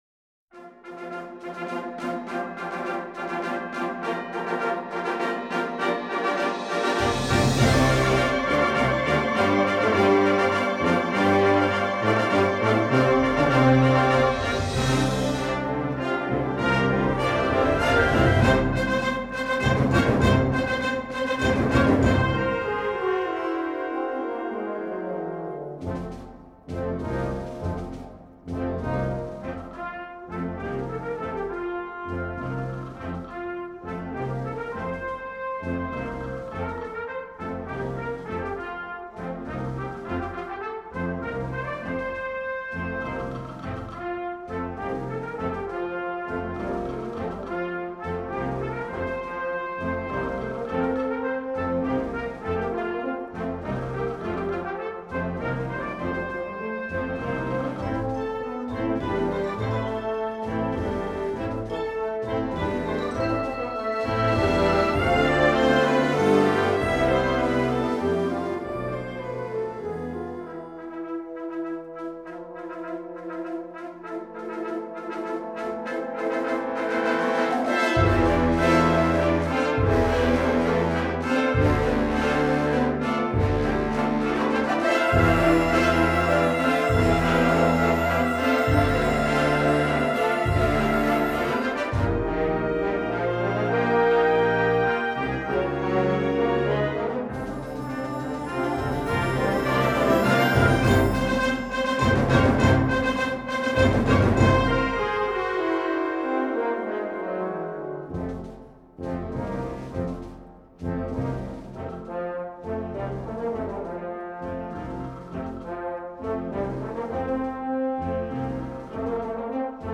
Harmonie/Fanfare/Brass-orkest
harmonieorkest